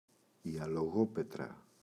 αλογόπετρα, η [aloꞋγopetra]